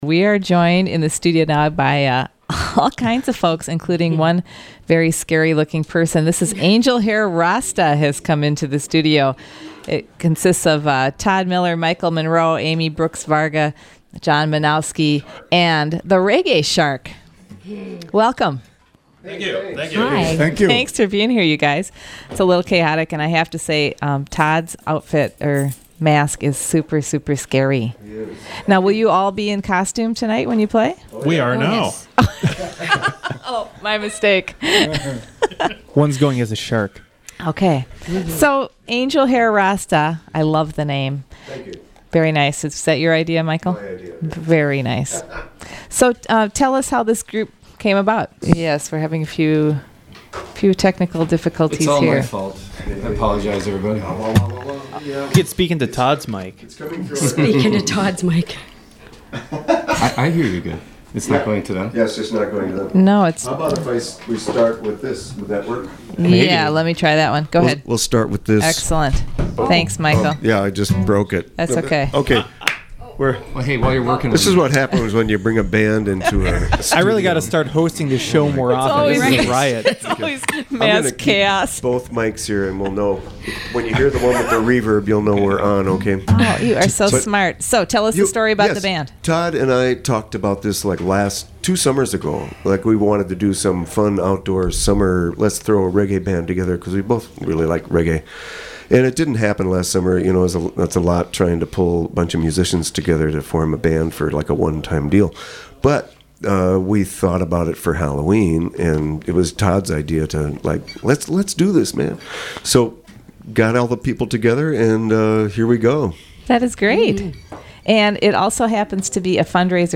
reggae
Live Music Archive